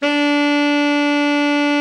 Index of /90_sSampleCDs/Giga Samples Collection/Sax/TENOR VEL-OB